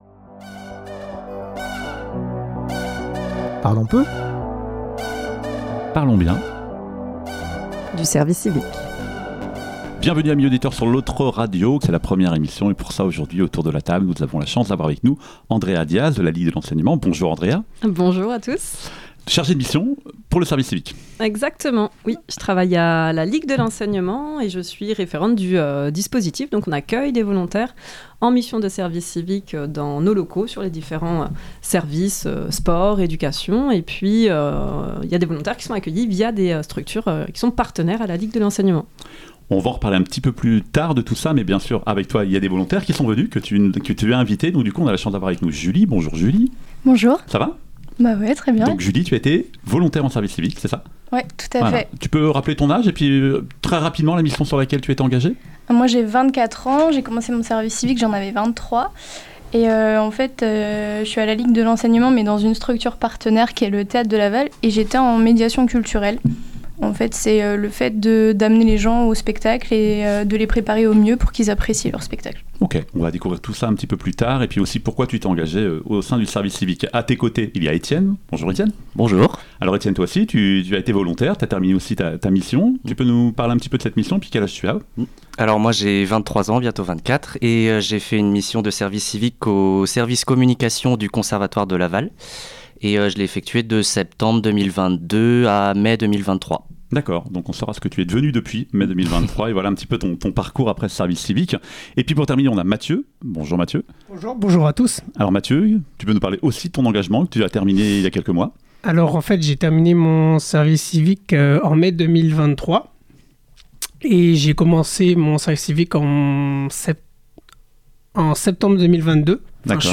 Nous avons reçu lors de cette première émission trois anciens volontaires en service civique.